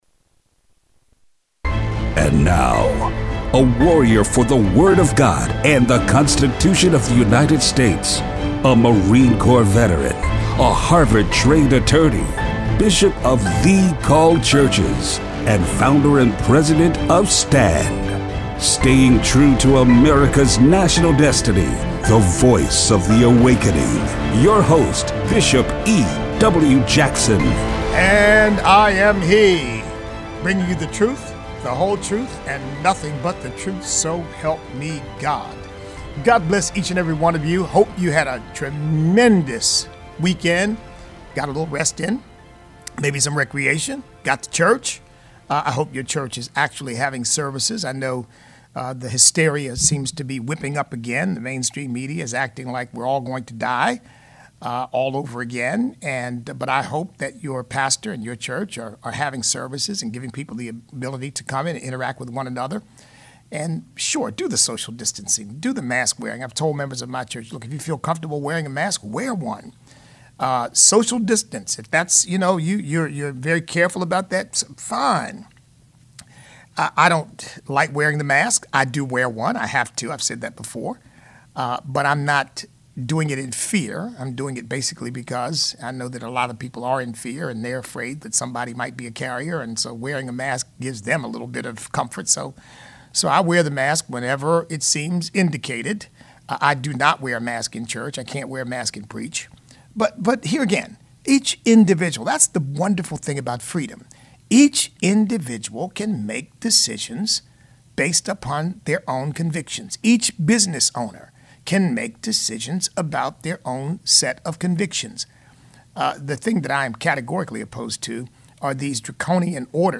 L.A. school teachers union says they will not restart schools until police are defunded. Listener call-in.